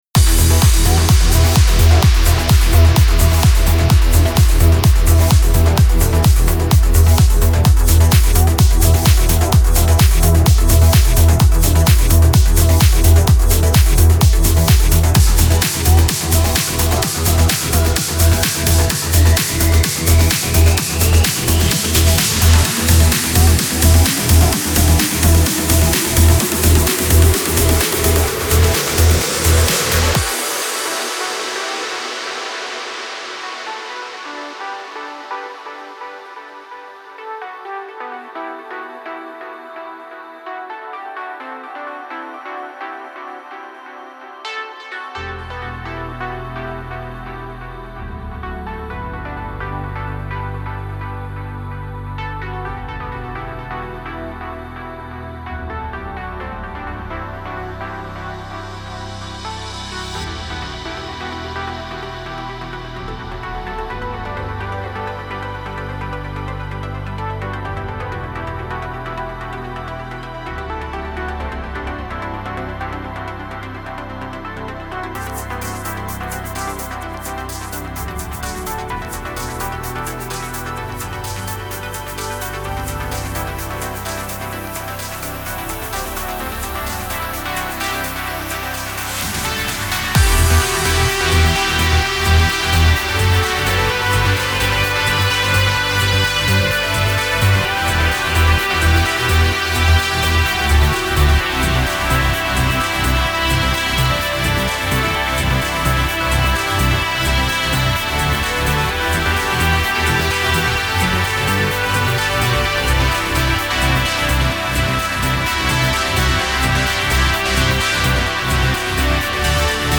موسیقی کنار تو
پر‌انرژی ترنس موسیقی بی کلام